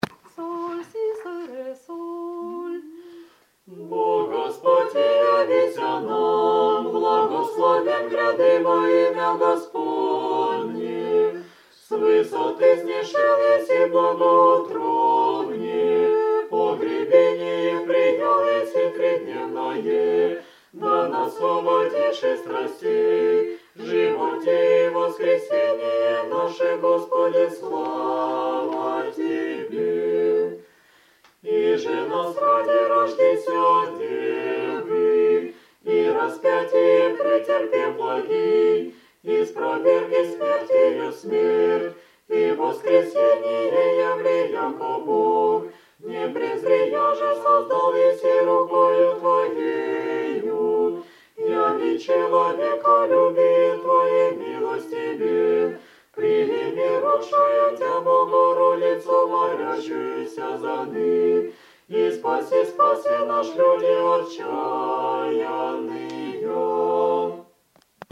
t8_troparnuy_kvartet.MP3.mp3